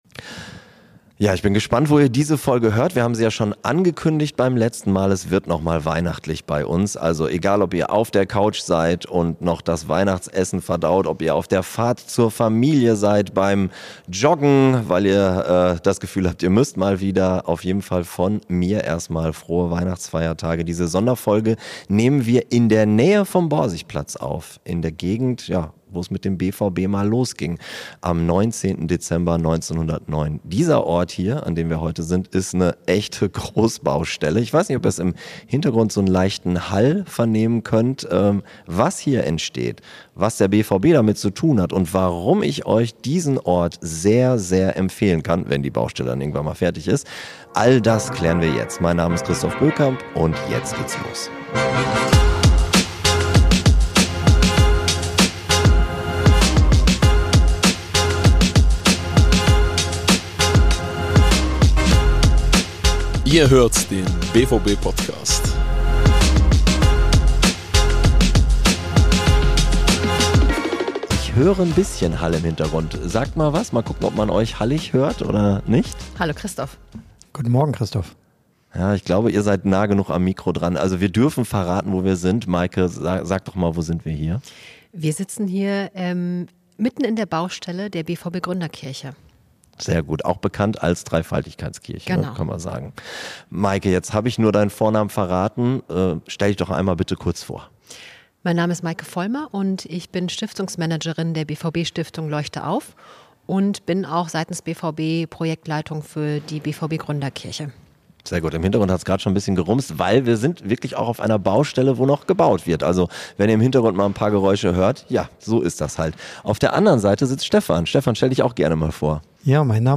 Beschreibung vor 3 Monaten Am 1. Weihnachtsfeiertag meldet sich der BVB-Podcast aus der Kirche.